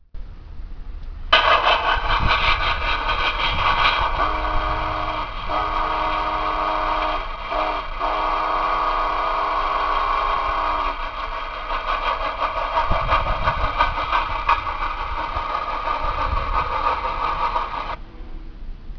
ＳＬの走行音、黄色を押すと鉄道唱歌が流れます。走行音の出所は分かりませんが、まぁまず宇和島鉄道のものでは無い筈です。